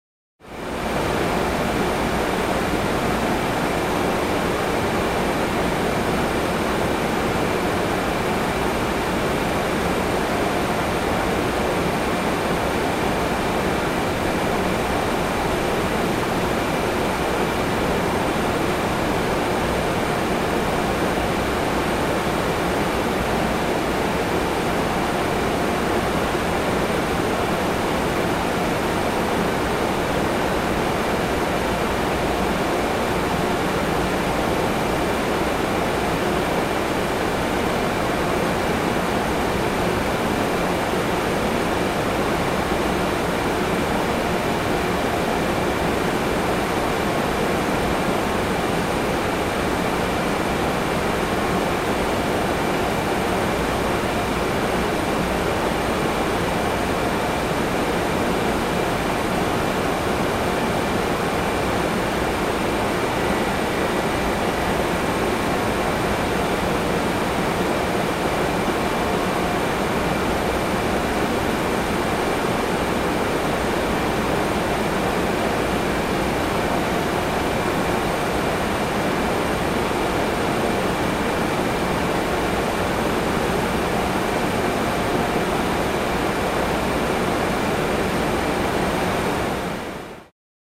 На этой странице собраны различные звуки работающего вентилятора: от тихого гула компьютерного кулера до мощного потока воздуха из бытового прибора.
Шум вентиляторов: В серверной работают вентиляторы